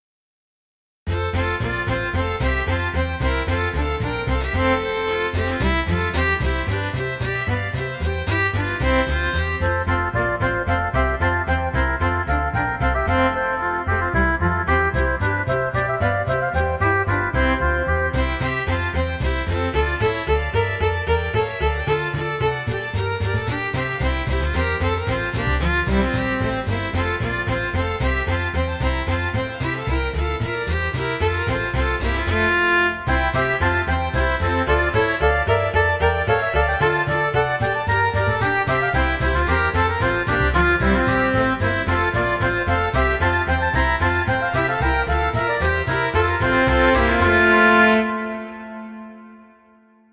Barroco
danza música sintetizador